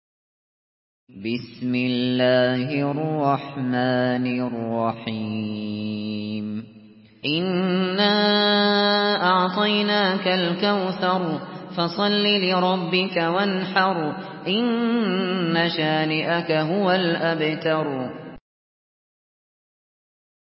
مرتل حفص عن عاصم